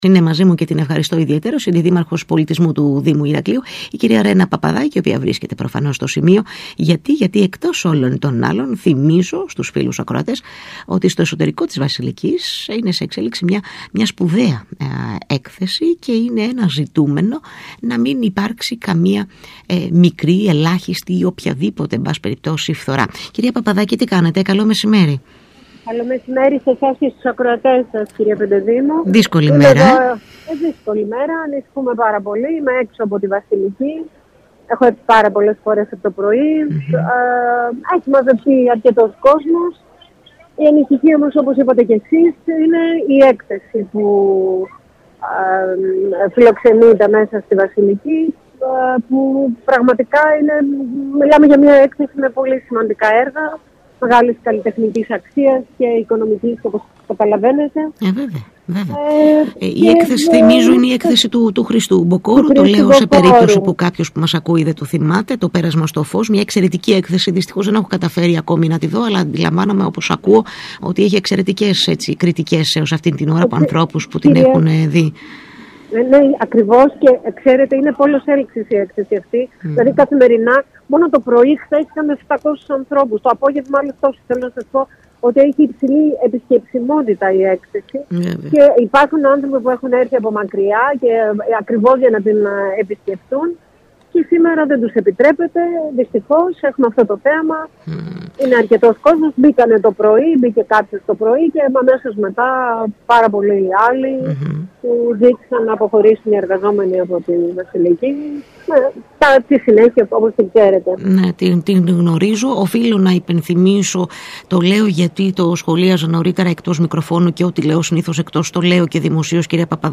Ακούστε εδώ όσα είπε η Αντιδήμαρχος Πολιτισμού Ρένα Παπαδάκη στον ΣΚΑΙ Κρήτης 92.1: